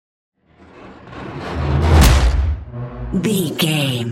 Whoosh to hit electronic
Sound Effects
Atonal
dark
futuristic
intense
tension